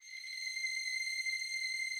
strings_084.wav